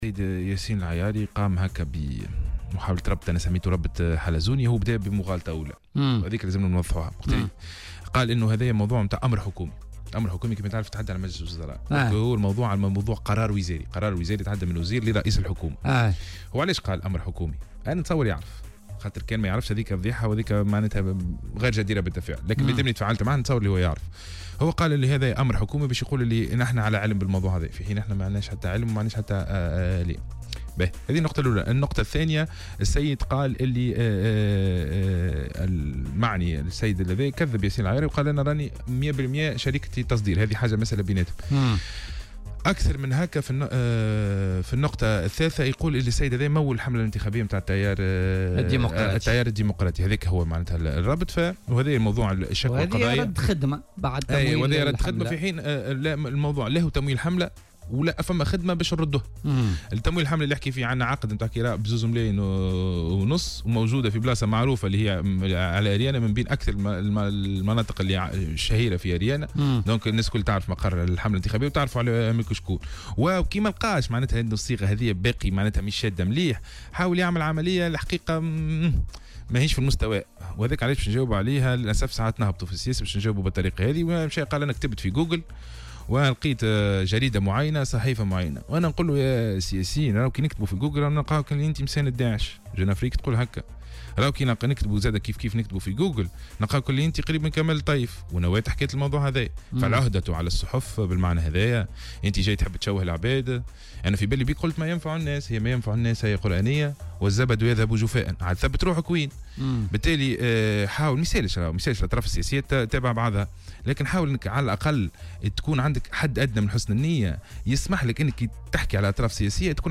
وأضاف في مداخلة له اليوم في برنامج "بوليتيكا" أن كل ما قيل مجرّد ادعاءات باطلة ولا أساس لها من الصحة، منتقدا لجوء العياري لمحرك البحث "Google" وما ينشر في بعض الصحف لاستسقاء المعلومات.